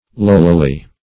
Search Result for " lowlily" : The Collaborative International Dictionary of English v.0.48: Lowlily \Low"li*ly\, adv.